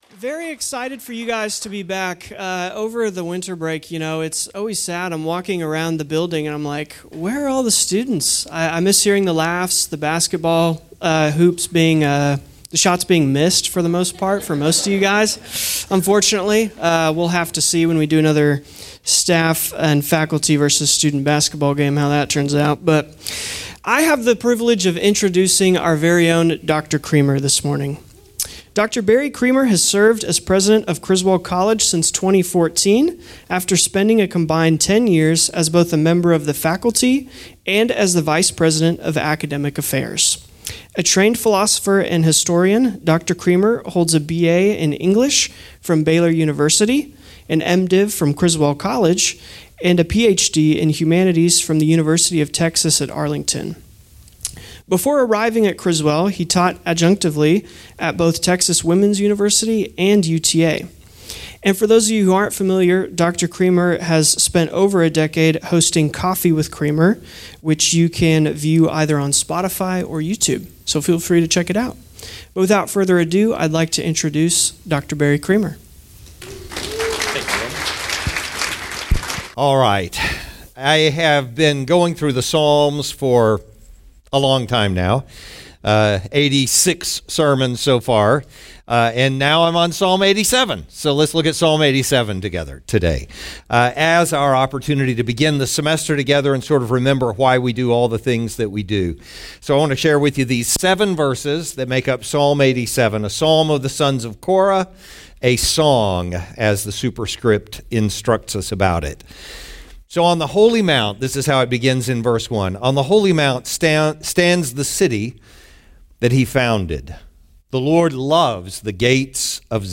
Criswell College Chapel Service.